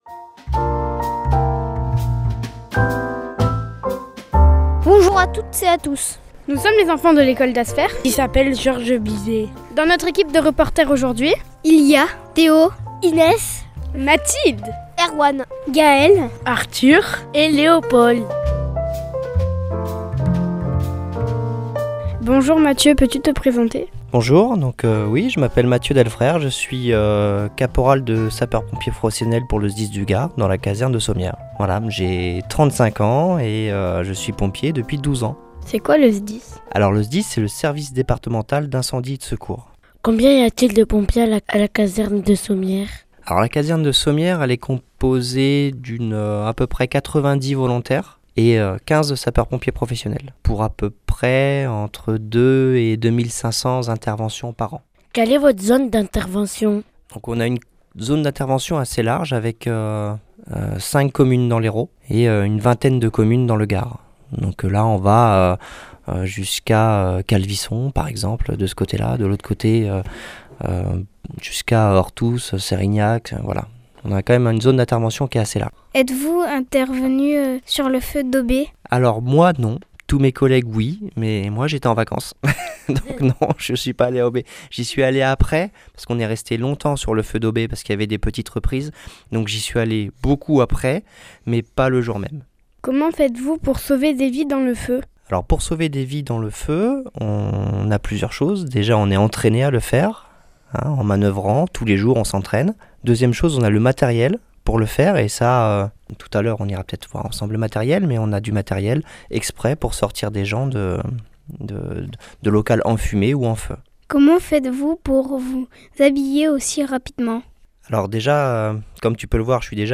Interview des pompiers de Sommières